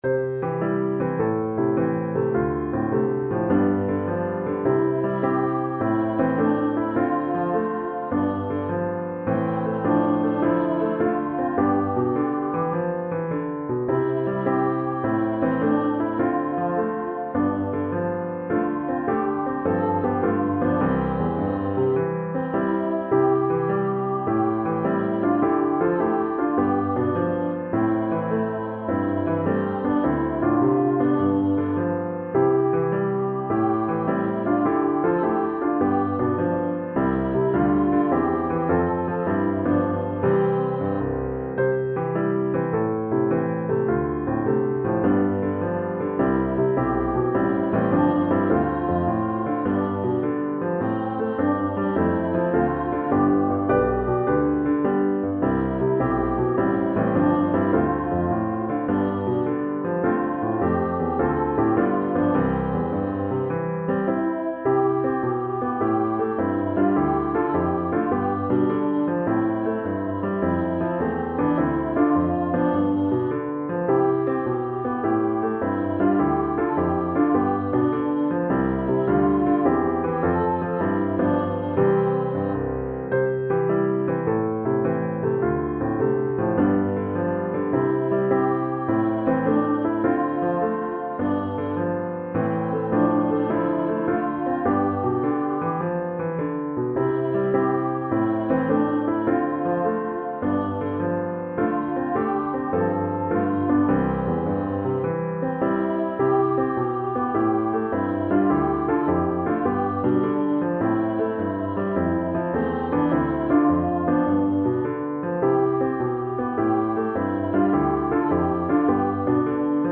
Backing Track
CoolHeads-Backing-Track.mp3